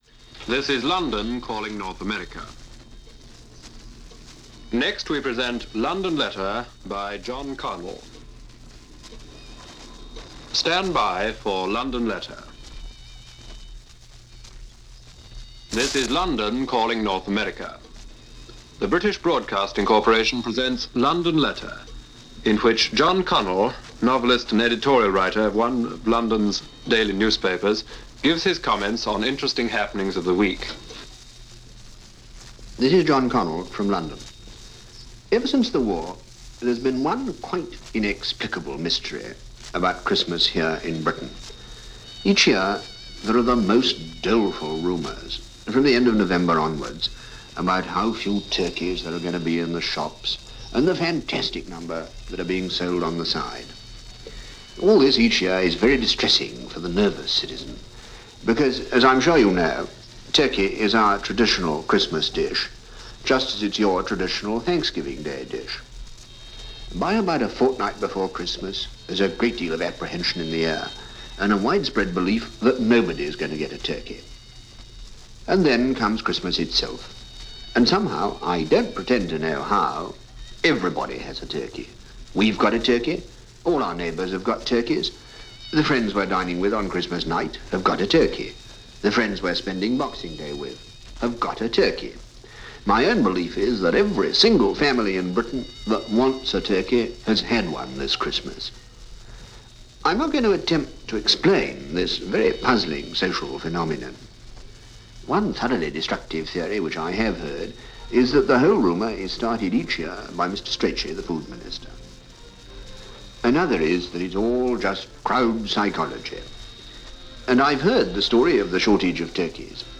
Holiday Spirit in Post-War Europe - 1948 - Report from London via the BBC North American Service Program "London Letter" December 1948.